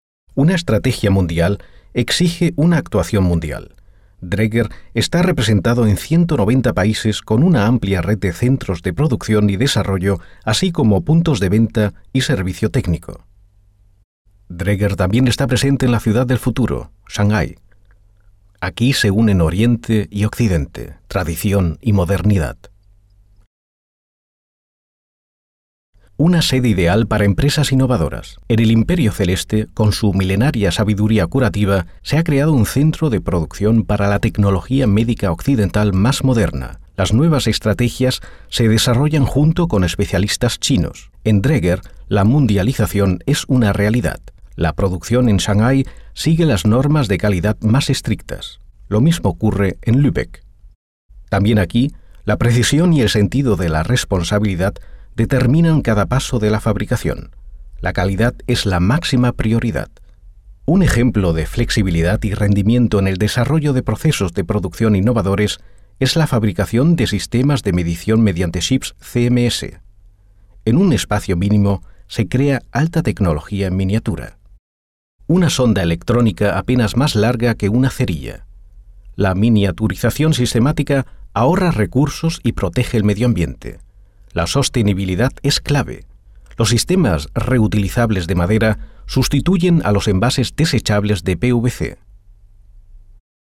Kein Dialekt
Sprechprobe: Industrie (Muttersprache):
spanish voice over talent